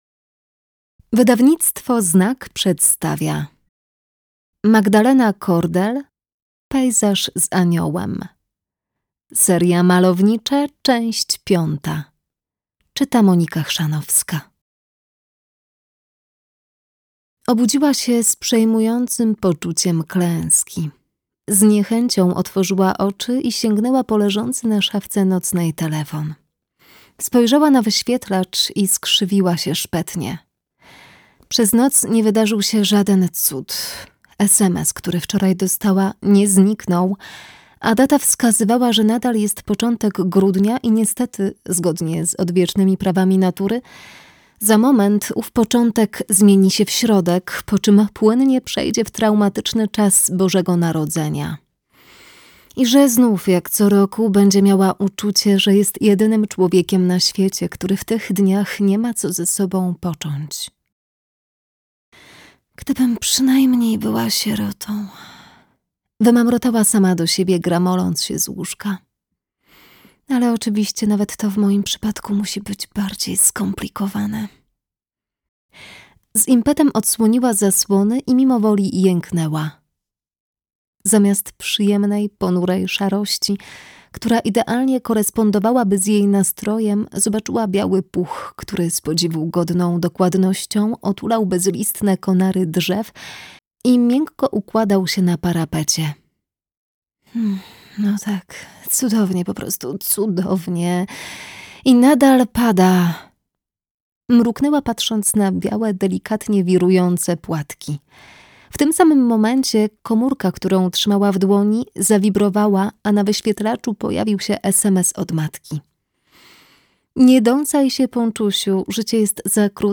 Pejzaż z aniołem - Magdalena Kordel - audiobook + książka